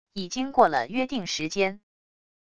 已经过了约定时间wav音频生成系统WAV Audio Player